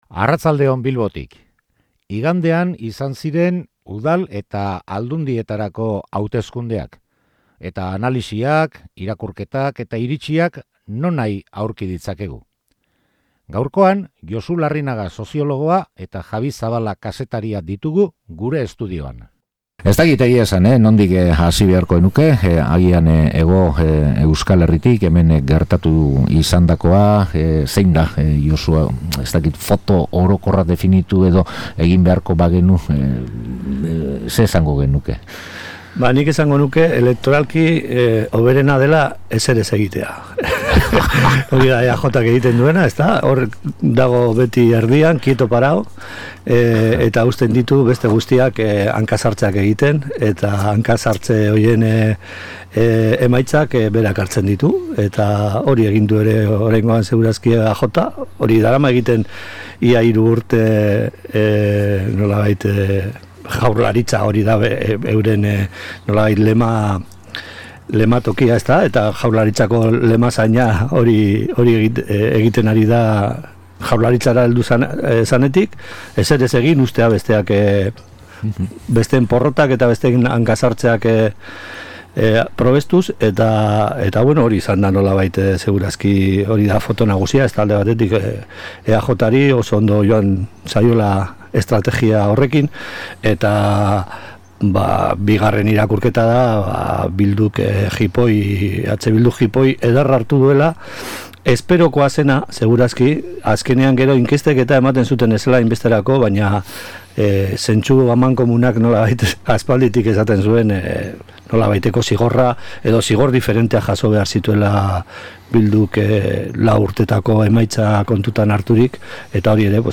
Igandeko udal eta foru bozen emaitzek luzerako zeresana eragingo dute oraindik, eta elkarrizketaren tartean ere horri heldu diogu.